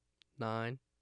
3. Spectrogram and AIF tracks for speech utterance “